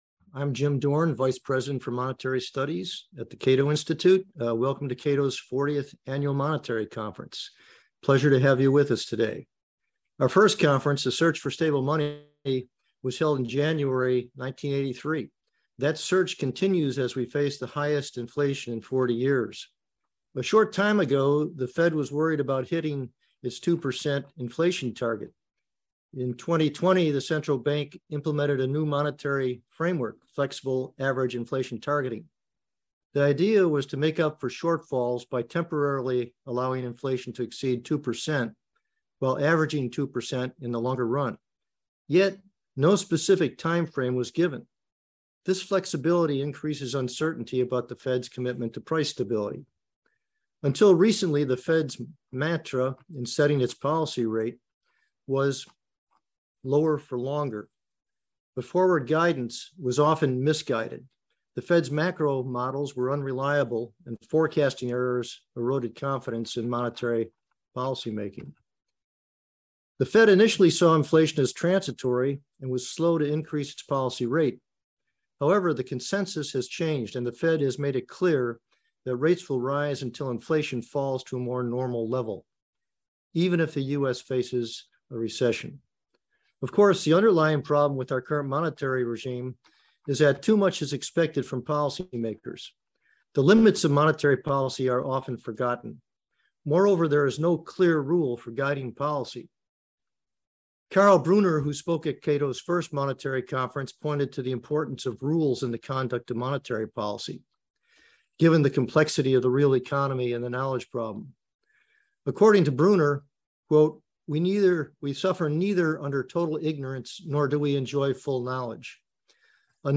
A Conversation with Jerome Powell - Cato Institute 40th Annual Monetary Conference